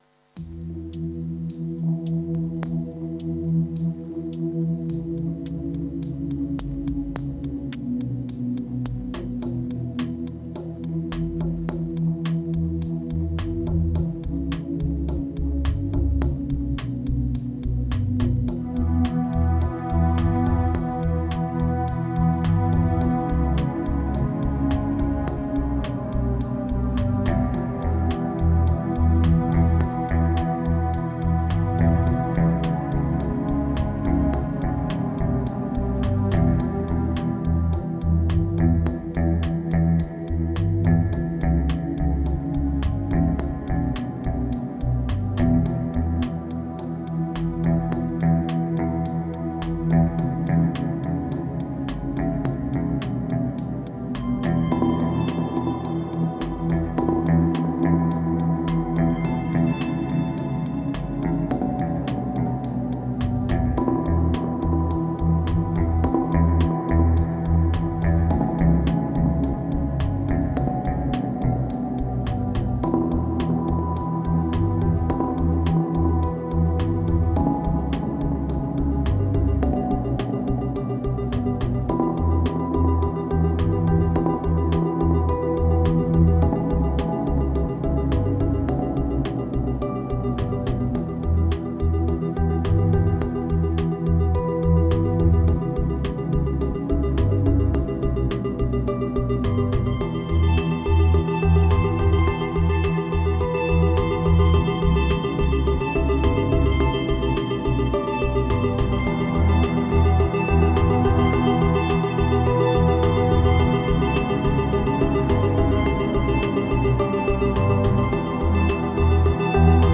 491📈 - 79%🤔 - 53BPM🔊 - 2022-03-30📅 - 719🌟